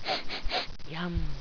sniff.wav